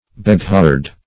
beghard - definition of beghard - synonyms, pronunciation, spelling from Free Dictionary
Beghard \Be*ghard"\ Beguard \Be*guard"\, n. [F. b['e]gard,